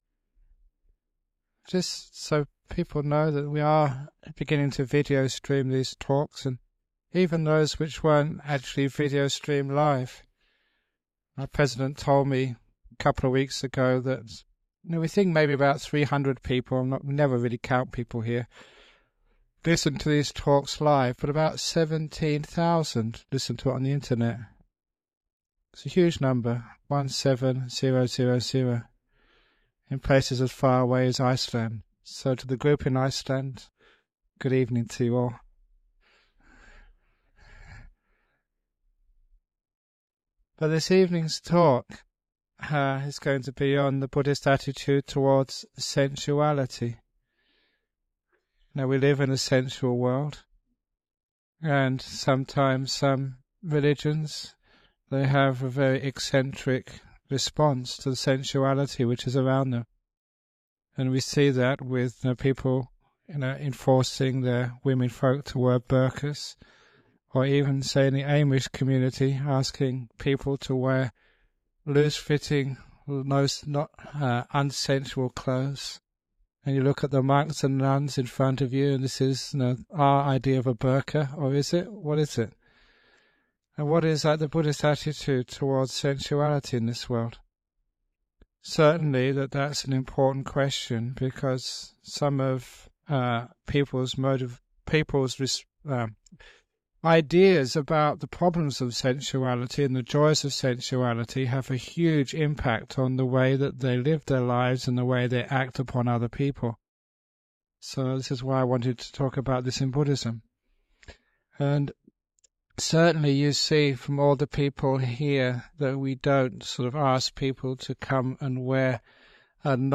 Ajahn Brahm gives a talk about the Buddhist attitude towards sensuality.